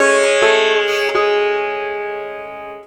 SITAR LINE19.wav